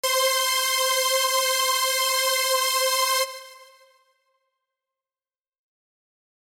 For Harmless users, select the warming preset under Compression and set the mix value to approximately 60%; this of course gives us New York style compression.